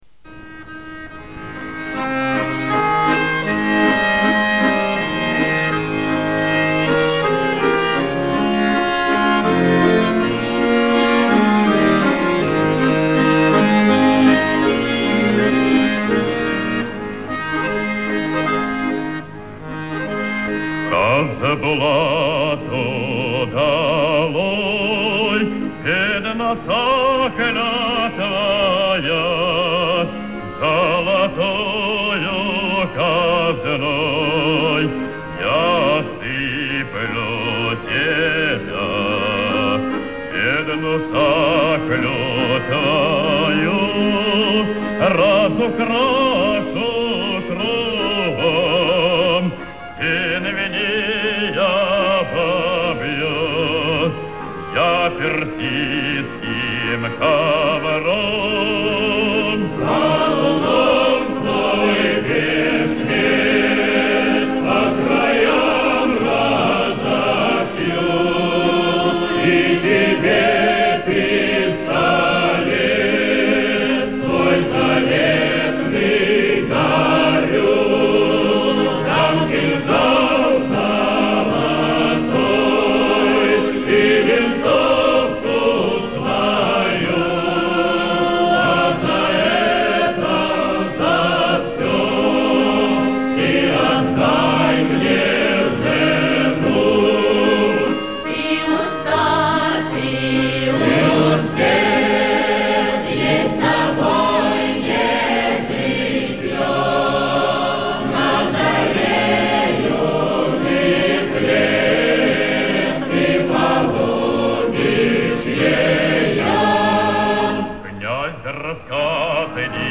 Русские народные песни